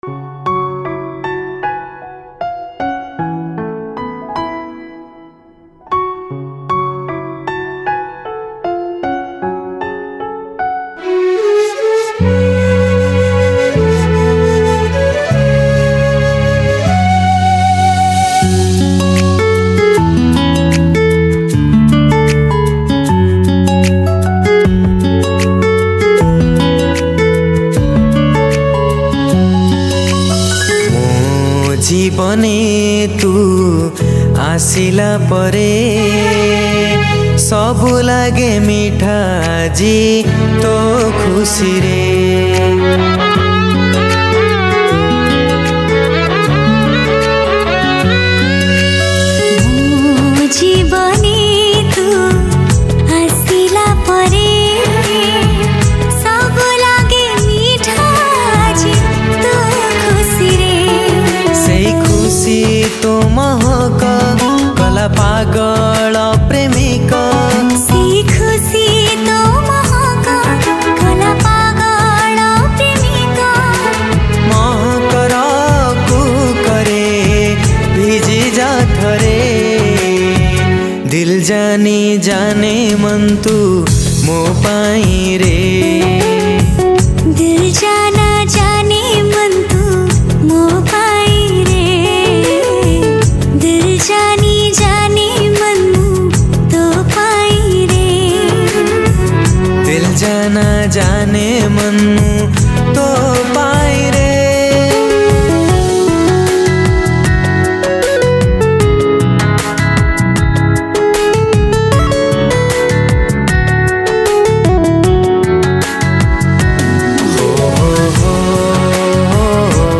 Odia New Romantic Song